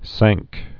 (săngk)